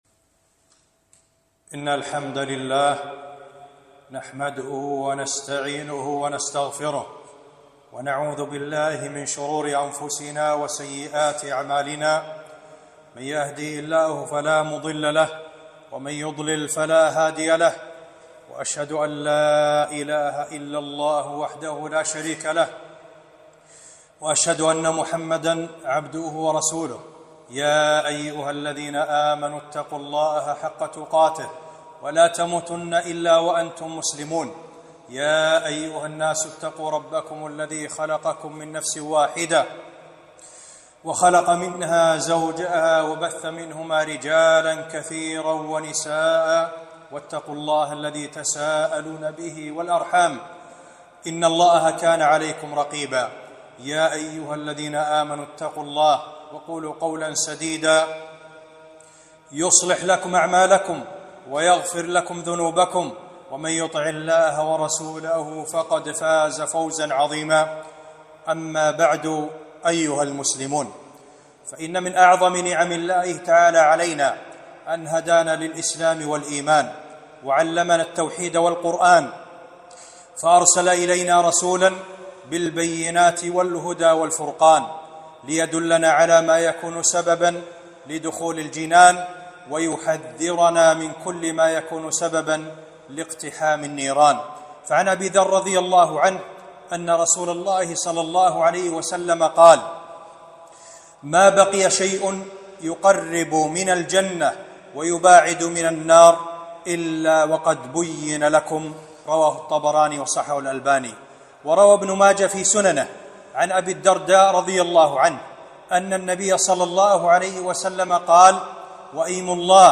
خطبة - من آثار الذنوب والمعاصي